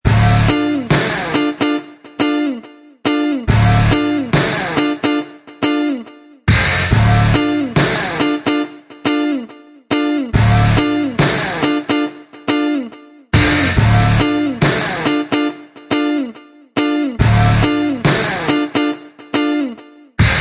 Download Reggae sound effect for free.
Reggae